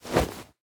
Minecraft Version Minecraft Version snapshot Latest Release | Latest Snapshot snapshot / assets / minecraft / sounds / item / bundle / drop_contents3.ogg Compare With Compare With Latest Release | Latest Snapshot
drop_contents3.ogg